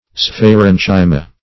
Search Result for " sphaerenchyma" : The Collaborative International Dictionary of English v.0.48: Sphaerenchyma \Sphae*ren"chy*ma\, n. [NL., fr. Gr.
sphaerenchyma.mp3